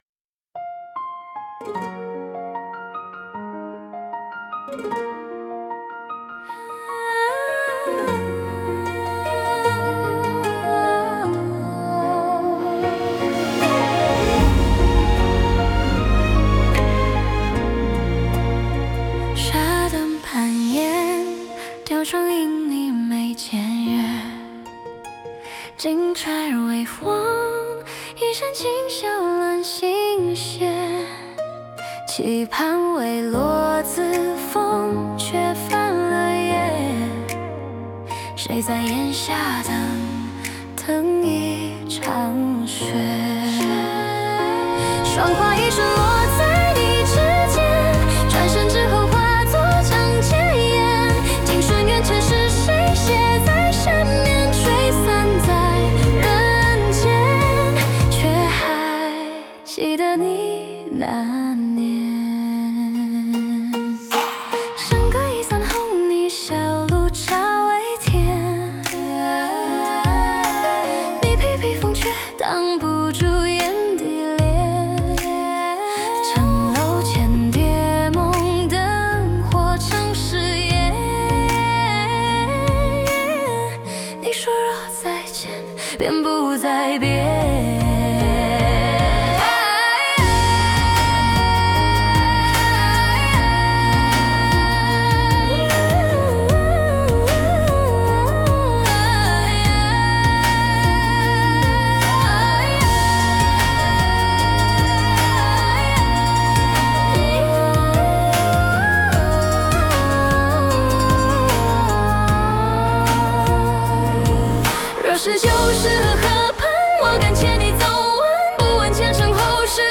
2:49 中国風 切ない感動的なバラードミュージック「霜花一瞬」